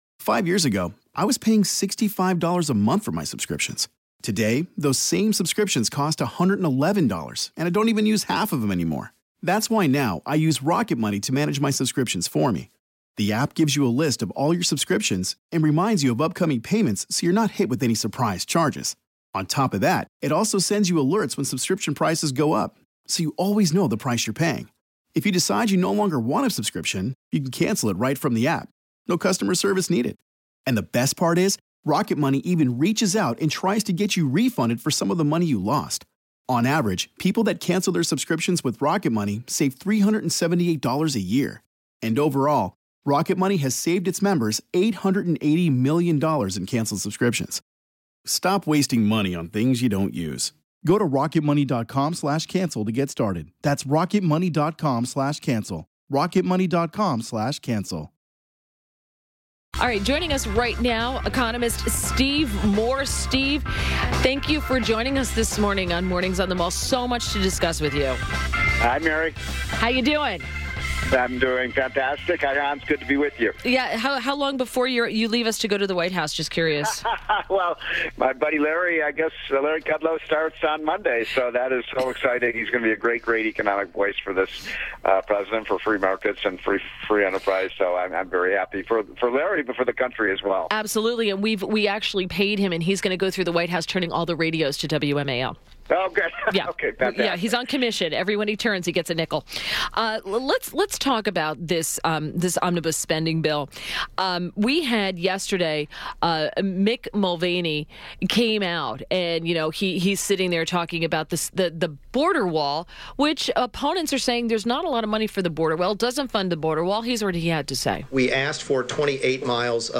WMAL Interview - STEVE MOORE - 03.23.18